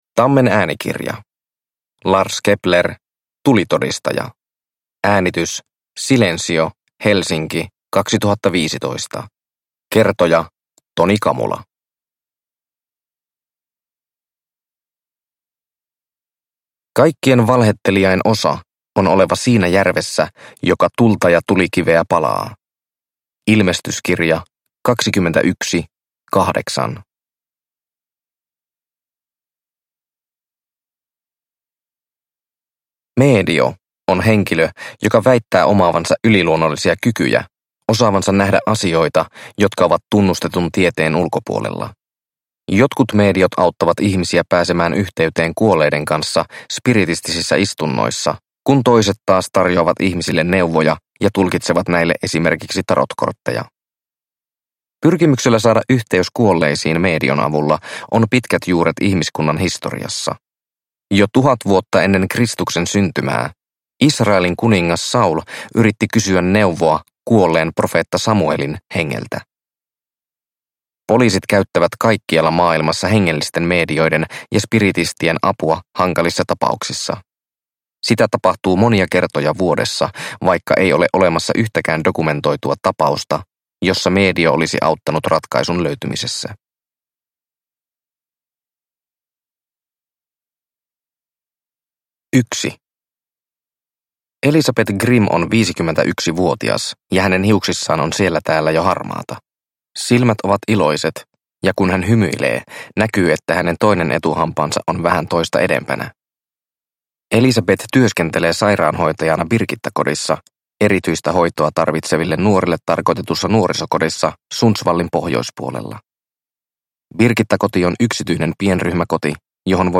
Tulitodistaja – Ljudbok – Laddas ner